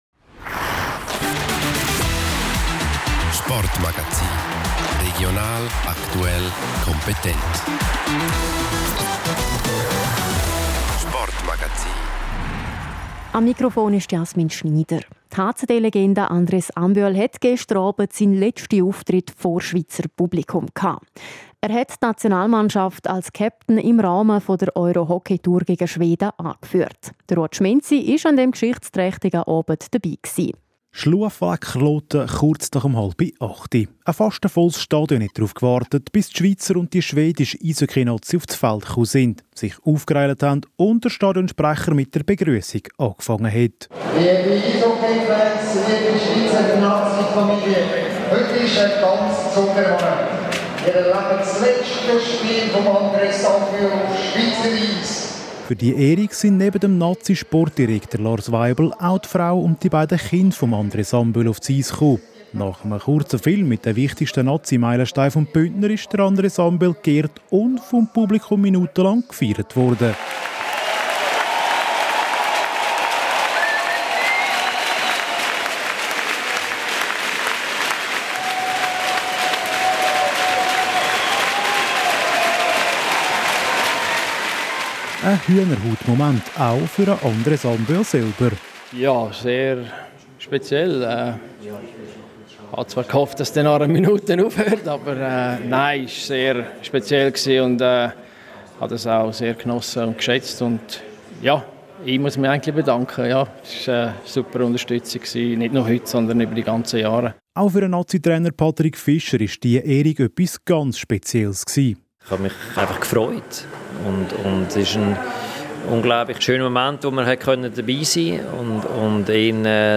• Minutenlang wurde Andres Ambühl bei seinem letzten Spiel auf Schweizer Eis beklatscht und bejubelt. Das Publikum hat ihn würdig verabschiedet.
Eine Reportage.